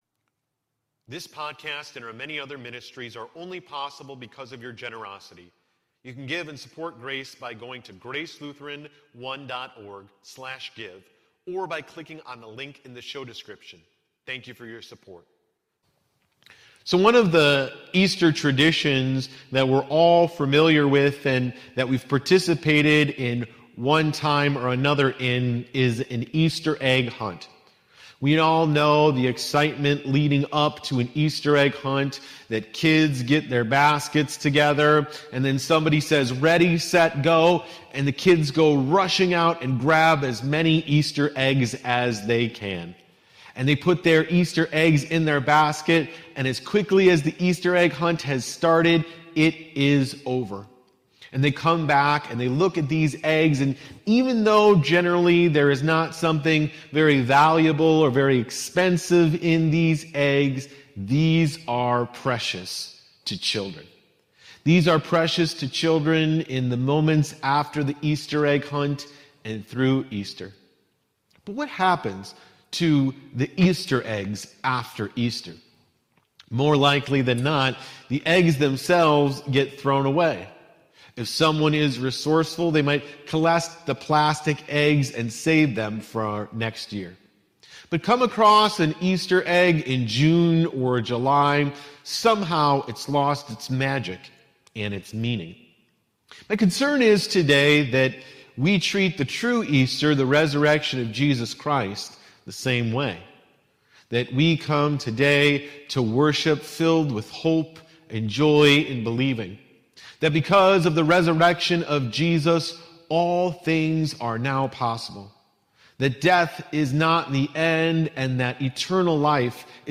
Sermon Easter 2022